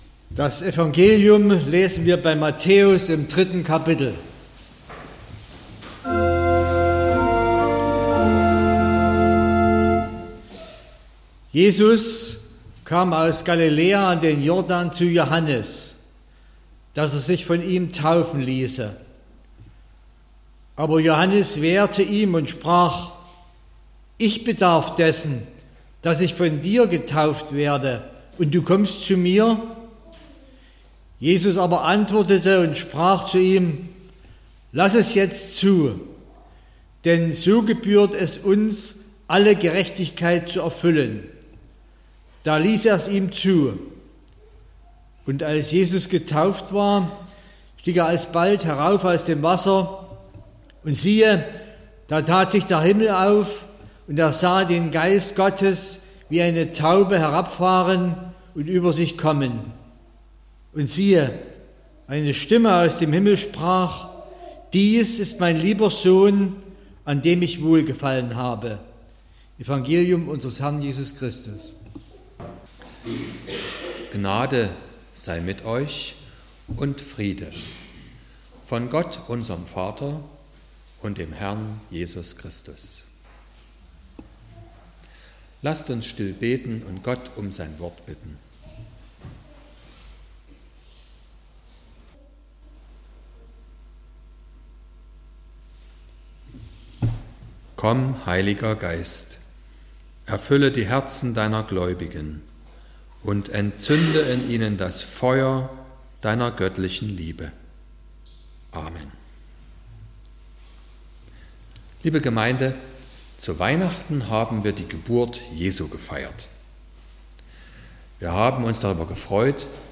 09.02.2025 – Gottesdienst
Predigt (Audio): 2025-02-09_Jesus__wer_bist_du__Die_Taufe_Jesu__Predigtreihe_2025__Thema_1_.mp3 (18,4 MB)